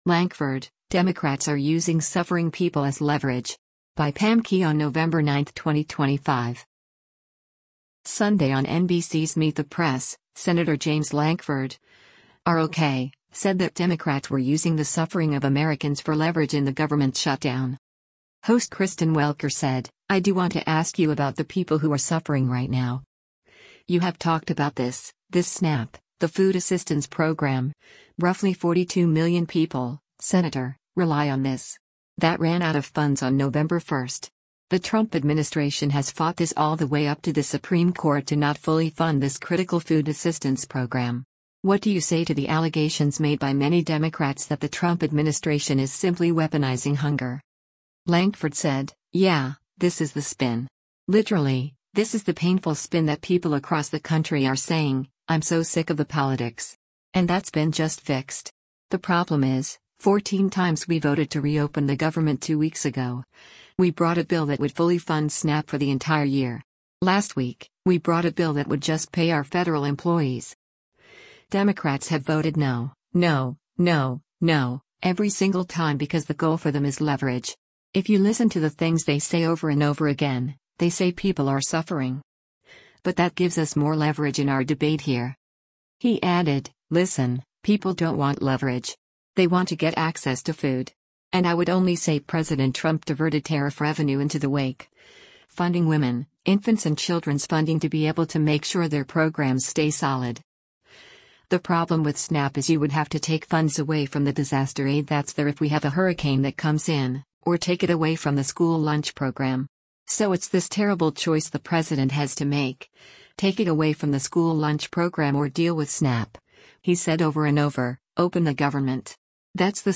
Sunday on NBC’s “Meet the Press,” Sen. James Lankford (R-OK) said that Democrats were using the suffering of Americans for “leverage” in the government shutdown.